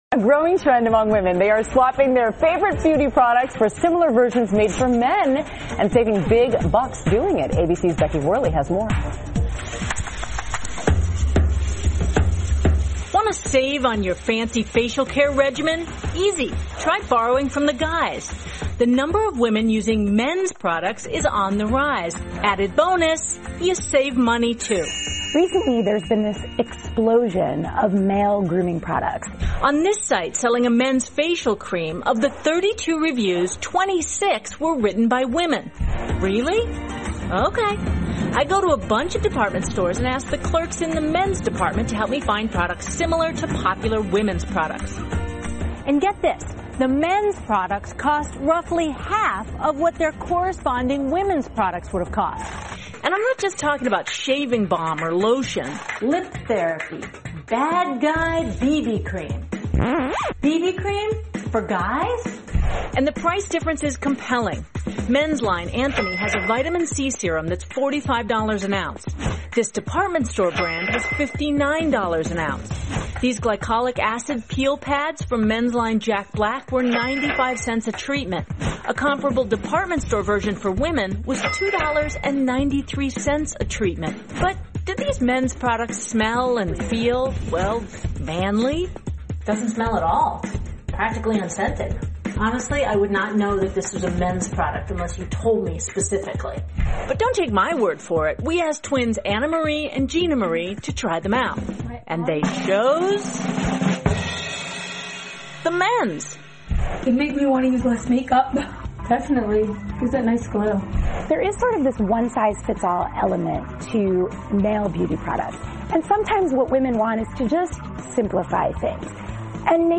访谈录 省钱，女性使用男士护肤品 听力文件下载—在线英语听力室